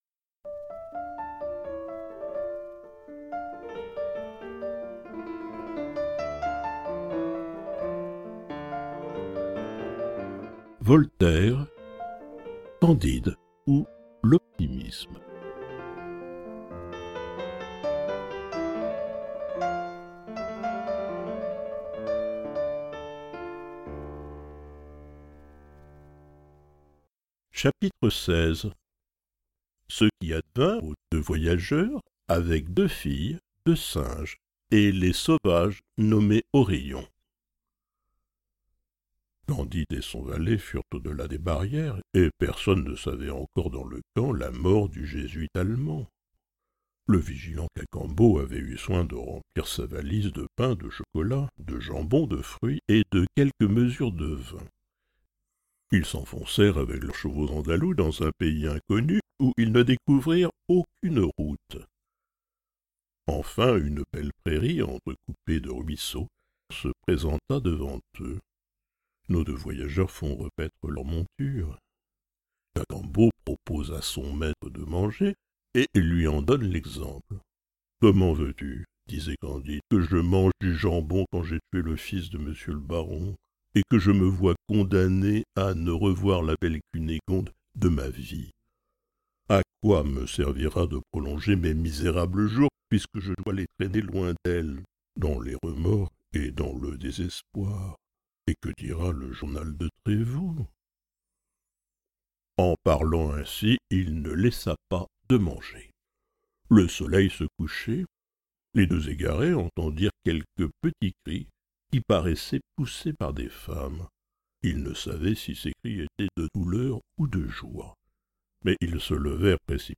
Lecture du Nègre de Surinam